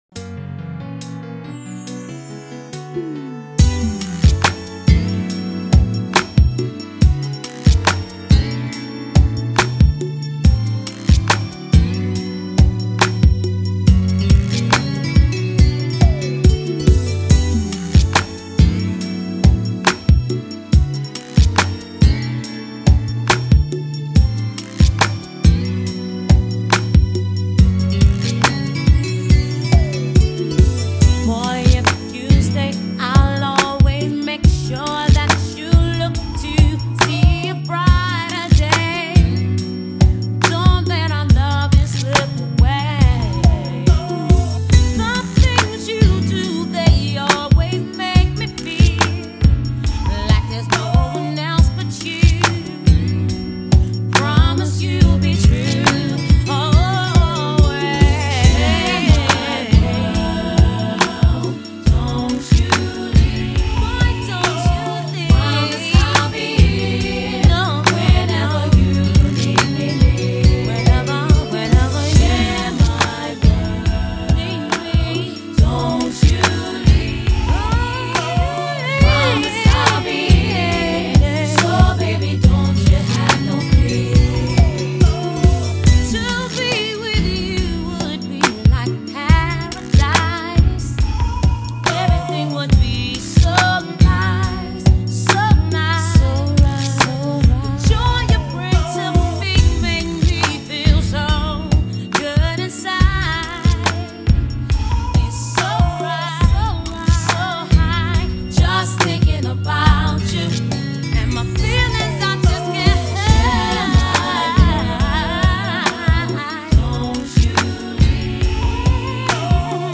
Old School RnB